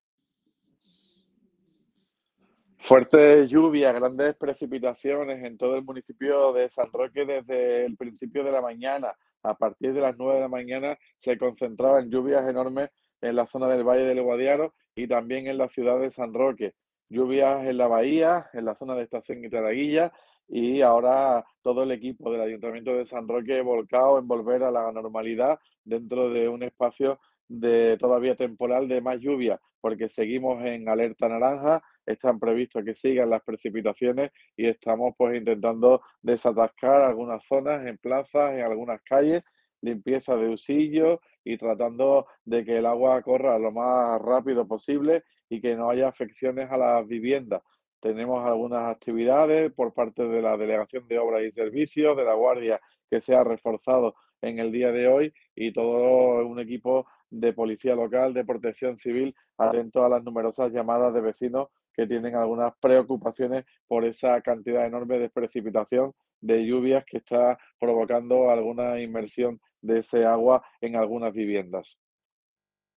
ALCALDE SOBRE LLUVIAS.mp3